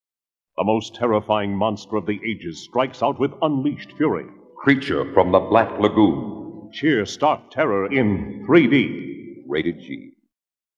1972 3D Radio Spots